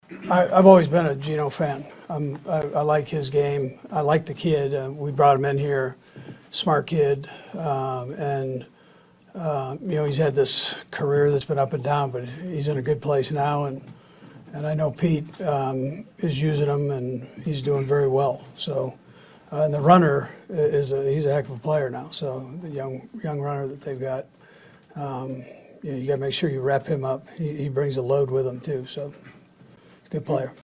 Chiefs Coach Andy Reid says he has a lot of respect for Seattle quarterback Geno Smith.
12-24-andy-reid-on-seattle.mp3